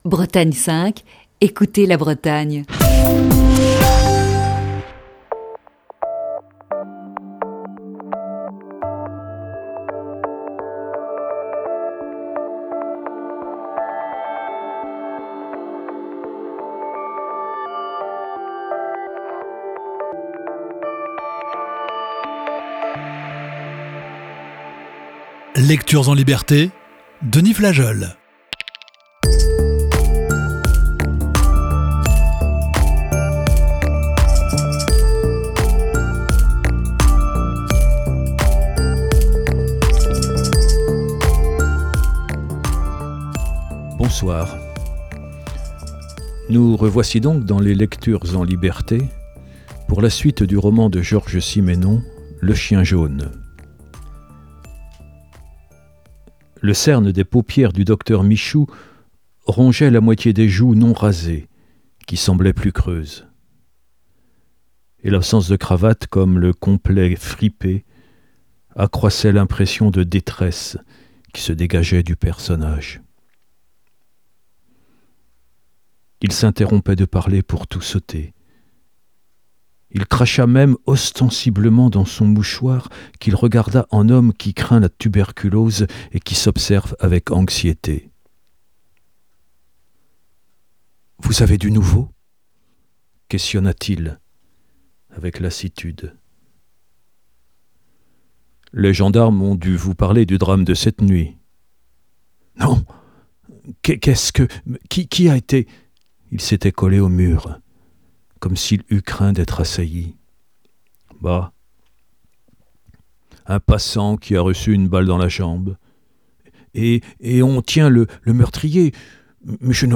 Émission du 9 juillet 2020. Pause estivale pour Lecture(s) en liberté.